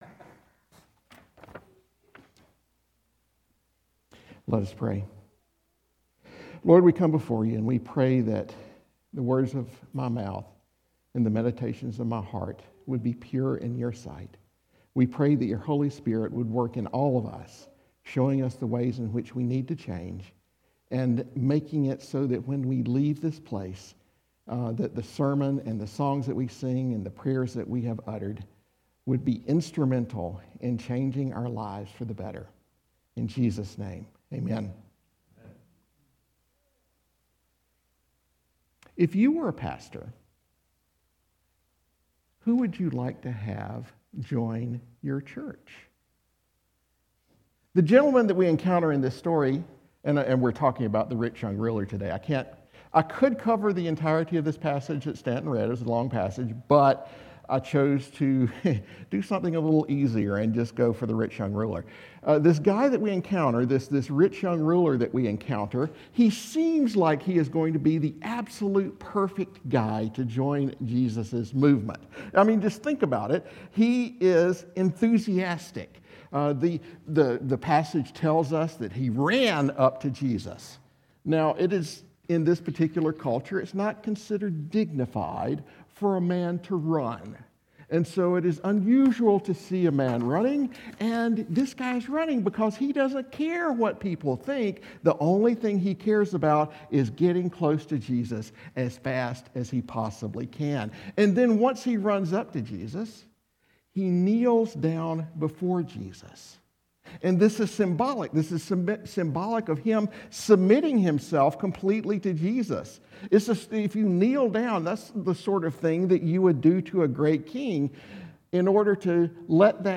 Sermons by St. Andrews Anglican Church - タブレット、携帯電話、またはブラウザで Second Sunday of Christmas - HC [January 05, 2025] (Year 1 C) / Broadcast×St. Andrews Anglican Church を今すぐ聴こう。